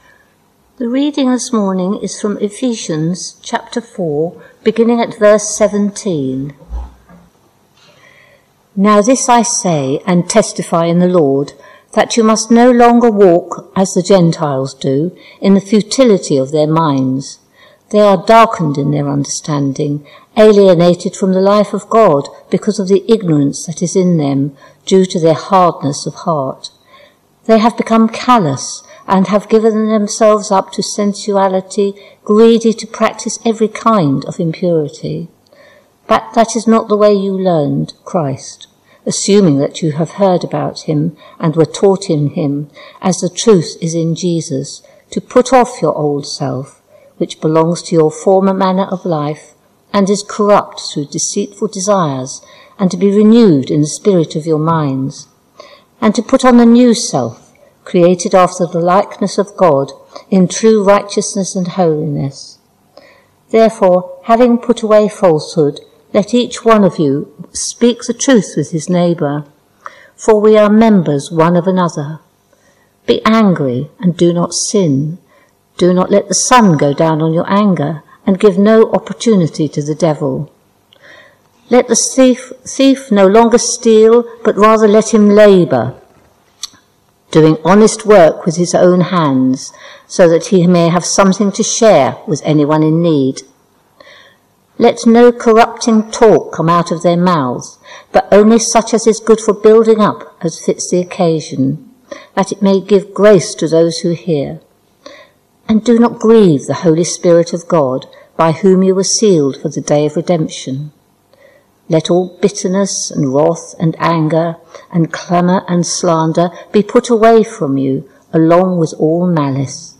Ephesians Passage: Ephesians 4:17-32 Service Type: Morning Service Topics